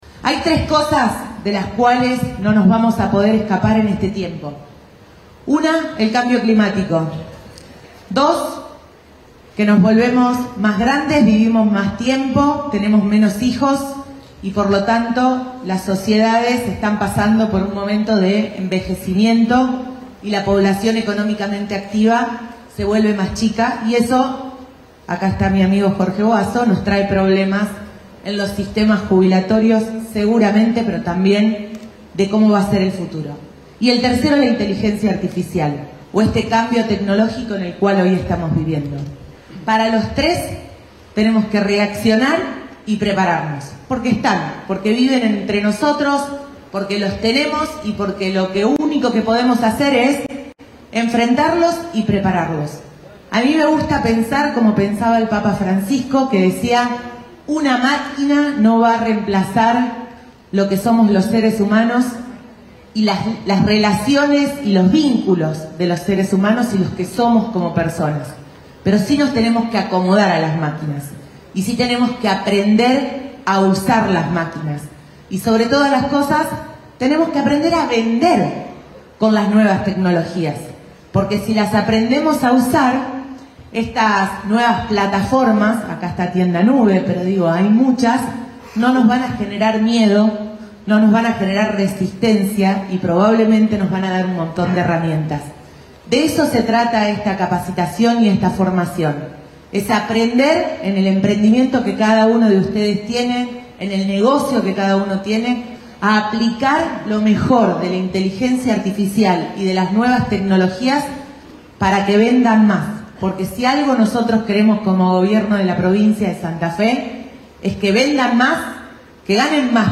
Lo afirmó la vicegobernadora en el Bioceres Arena de Rosario al presentar el programa H.O.L.A Futuro, acompañada por el ministro de Trabajo, Roald Báscolo.
Fragmentos de los discursos de Scaglia y Báscolo